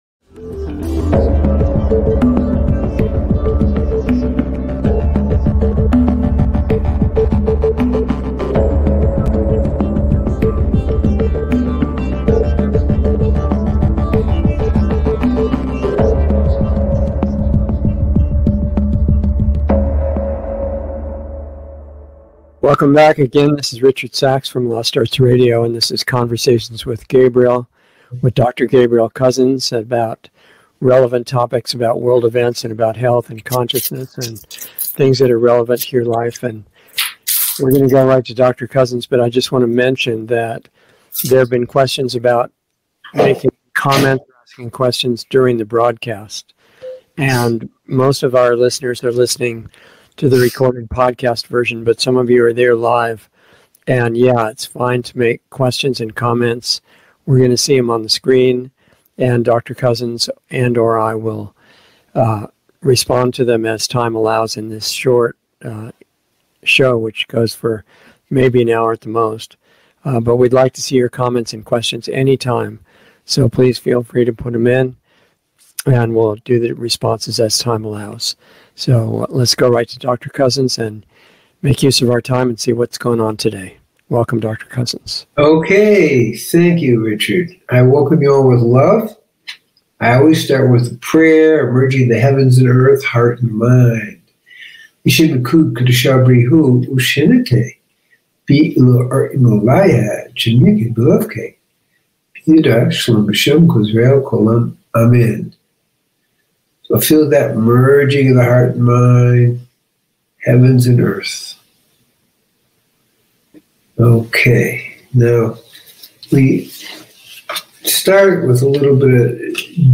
A new LIVE series